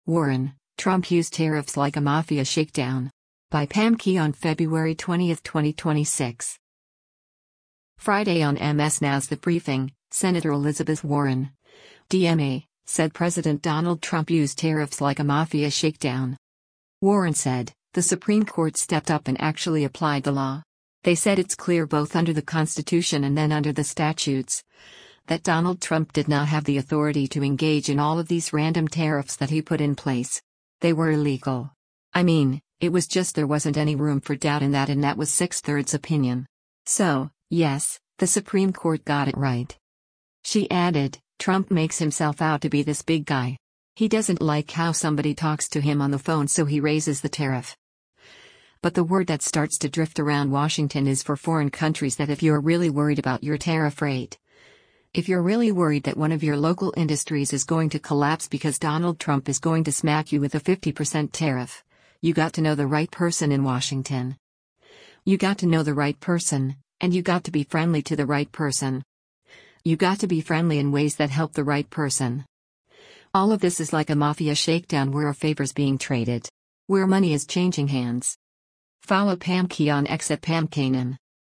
Friday on MS NOW’s “The Briefing,” Sen. Elizabeth Warren (D-MA) said President Donald Trump used tariffs “like a mafia shakedown.”